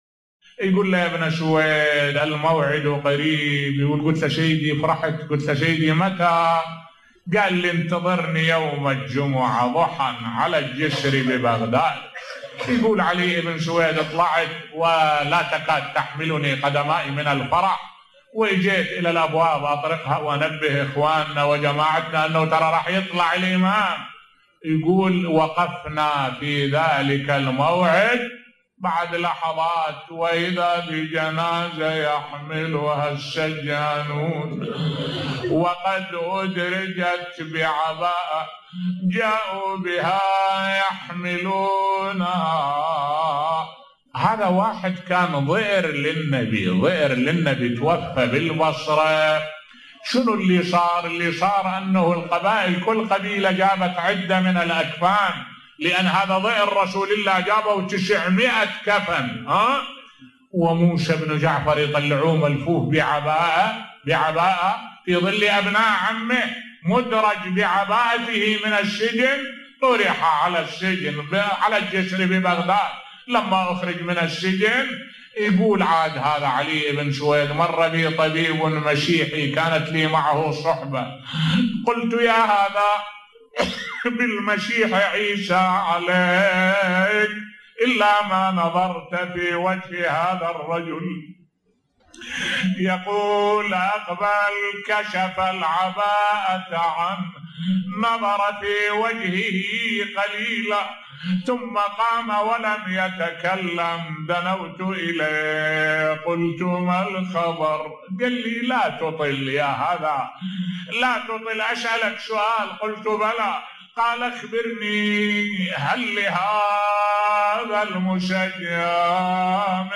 ملف صوتی استشهاد الإمام الكاظم بصوت الشيخ الدكتور أحمد الوائلي